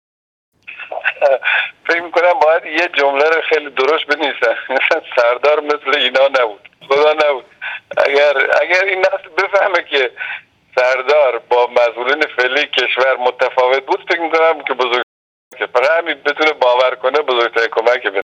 مصاحبه مناسبت شهادت حاج قاسم//// باید به نوجوانان این زمان بگوییم حاج قاسم شبیه مسئولان امروز نبود + صوت